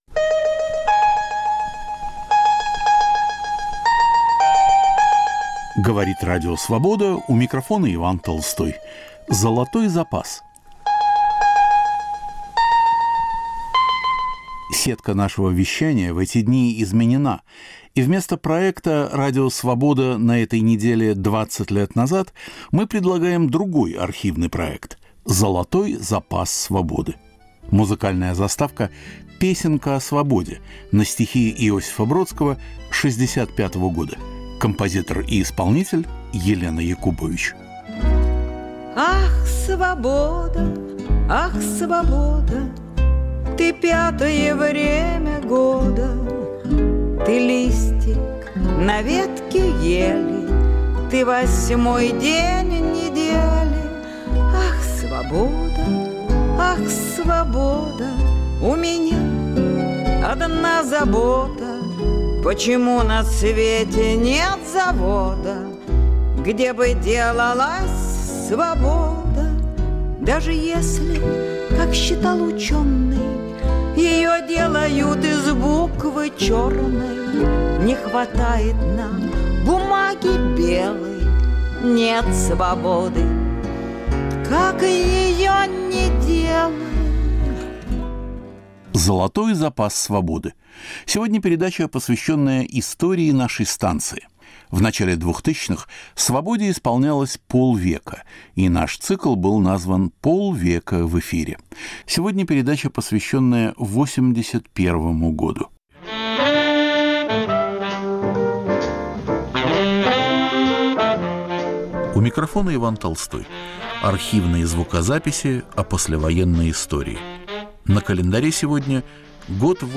К 50-летию Радио Свобода. Из архивов: у микрофона Сергей Довлатов, новая книга Абдурахмана Авторханова о Леониде Брежневе, Александр Солженицын начинает серию книг о новейшей истории России, 60 лет академику Сахарову, Александр Гинзбург о "Белой книге" по делу Синявского и Даниэля.